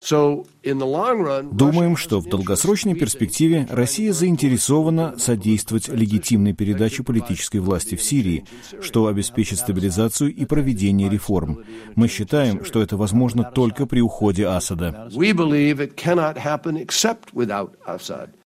Говорит госсекретарь США Джон Керри